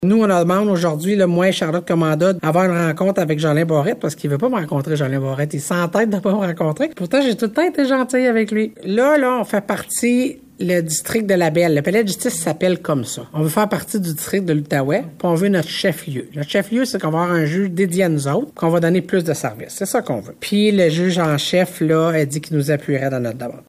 La préfète de la Vallée-de-la-Gatineau, Chantal Lamarche, explique la teneur de la demande :